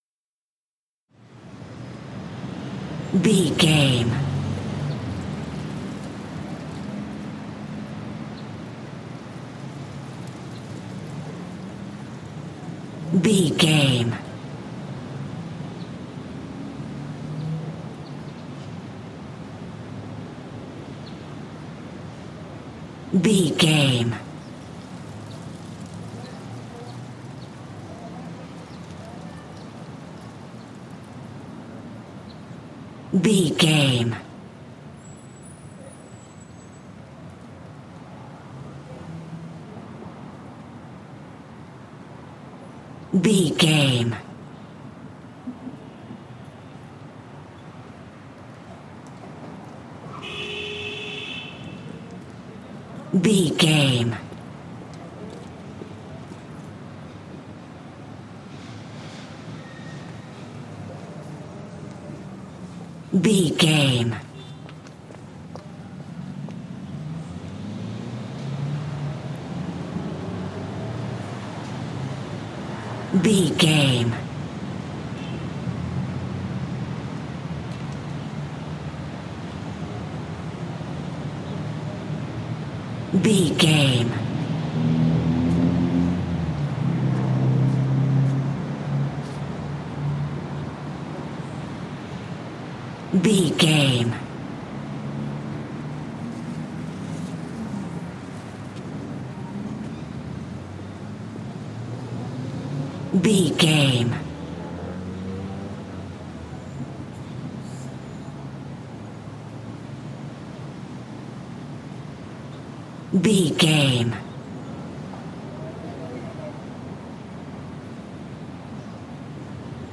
City calm street
City calm street 20
Sound Effects
urban
ambience